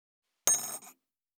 244,食器にスプーンを置く,ガラスがこすれあう擦れ合う音,,グラス,コップ,工具,小物,雑貨,コトン,
コップ効果音厨房/台所/レストラン/kitchen物を置く食器